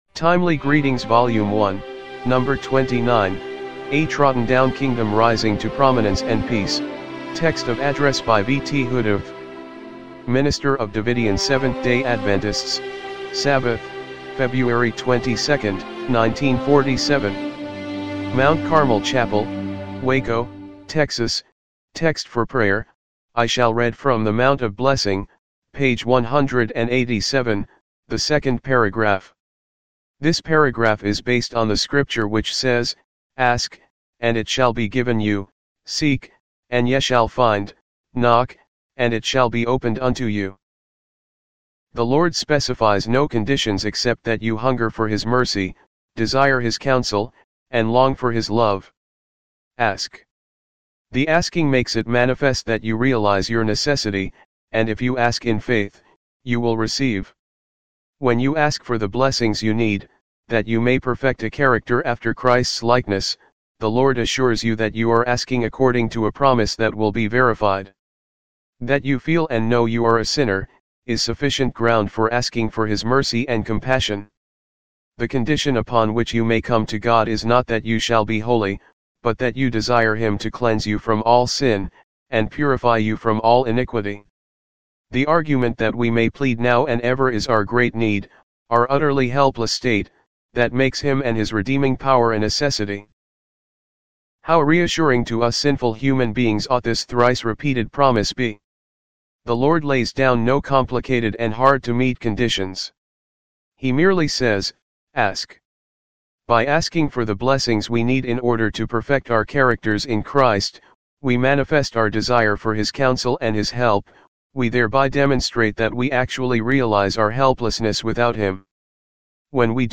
timely-greetings-volume-1-no.-29-mono-mp3.mp3